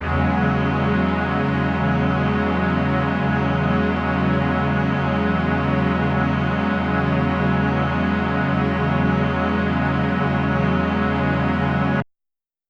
SO_KTron-Ensemble-E6:9.wav